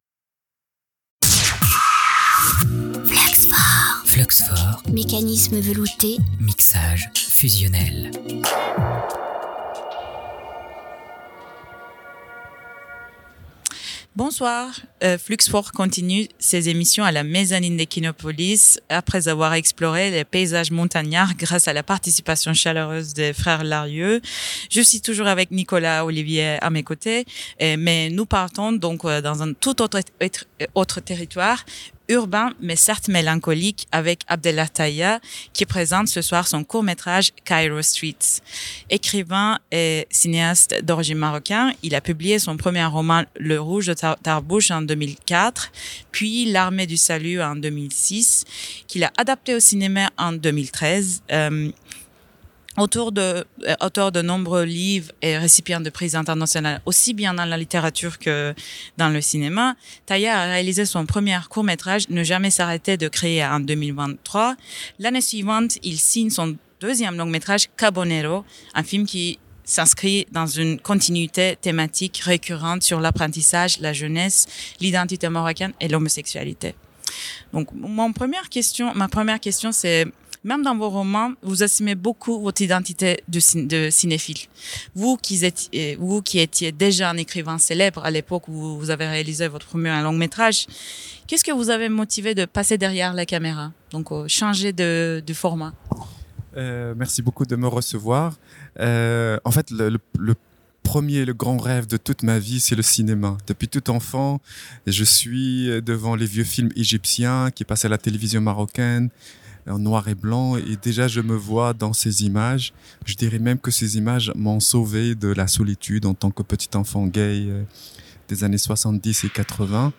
Rencontre avec Abdellah Taïa, Entrevues 2025